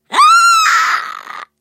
Звуки ведьмы